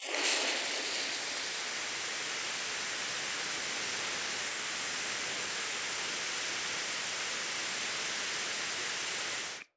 fuse.wav